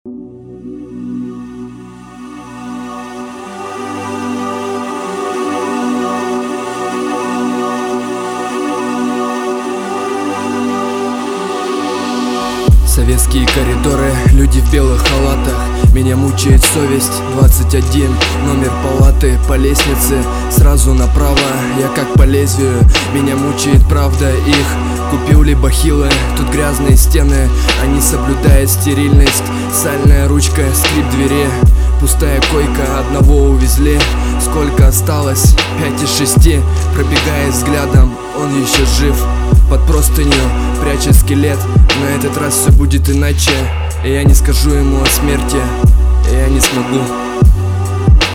Отрывистая проза, хоть и с сюжетом